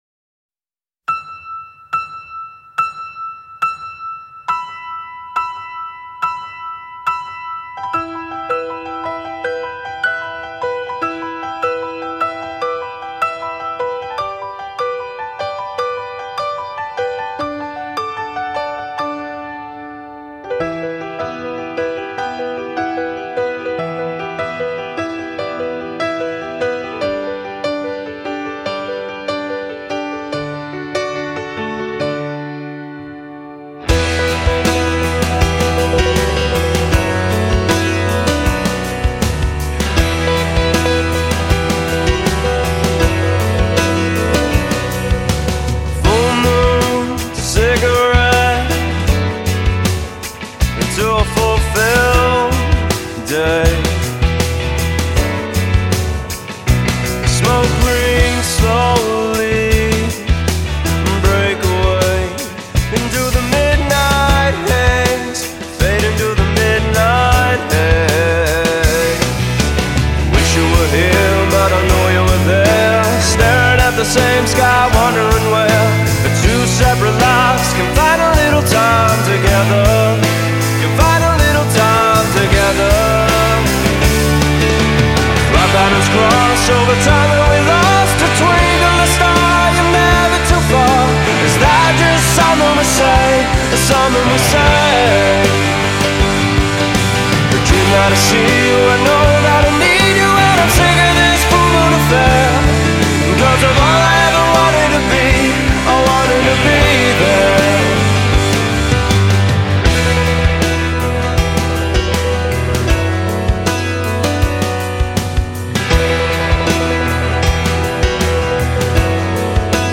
Despite a great voice and some TV soundtrack exposure